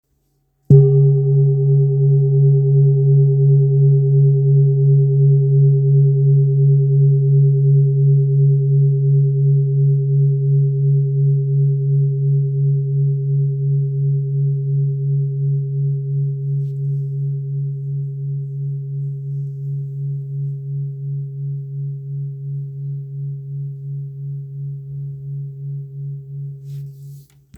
Kopre Singing Bowl, Buddhist Hand Beaten, Antique Finishing, 18 by 18 cm,
Material Seven Bronze Metal